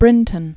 (brĭntən), Daniel Garrison 1837-1899.